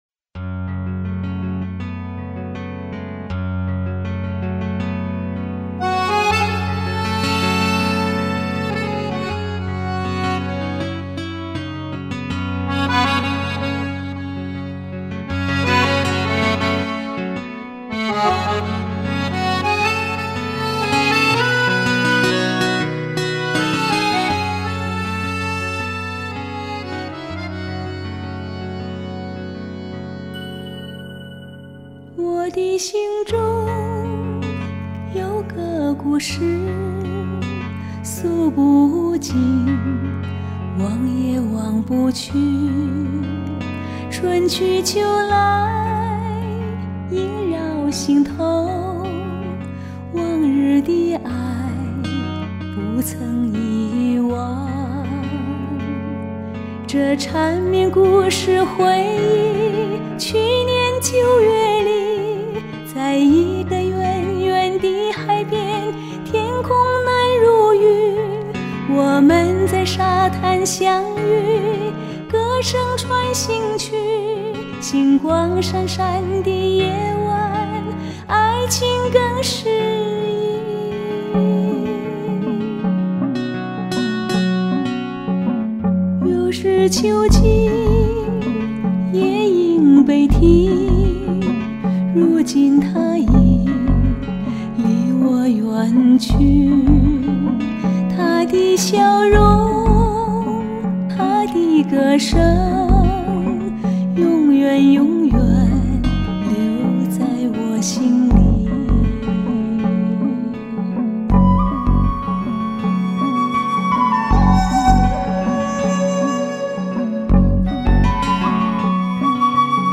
地方民歌、戏曲及其他歌类